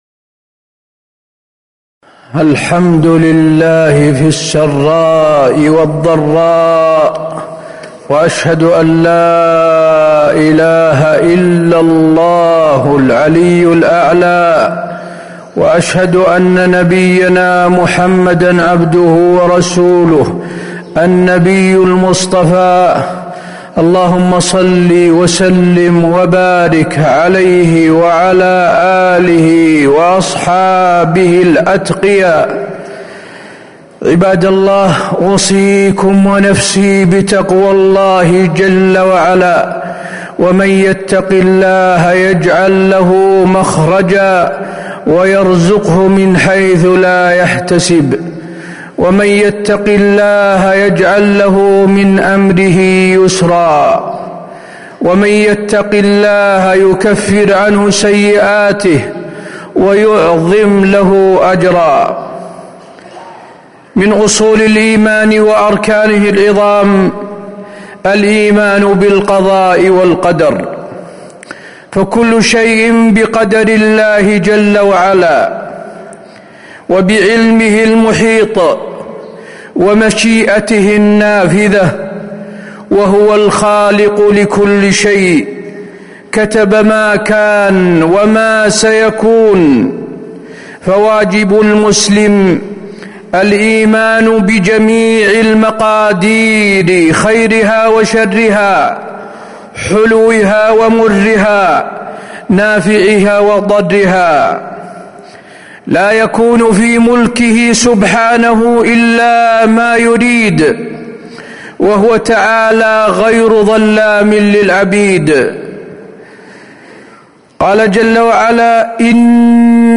تاريخ النشر ٧ ربيع الأول ١٤٤٥ هـ المكان: المسجد النبوي الشيخ: فضيلة الشيخ د. حسين بن عبدالعزيز آل الشيخ فضيلة الشيخ د. حسين بن عبدالعزيز آل الشيخ الإيمان بالقضاء والقدر